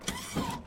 servostep.ogg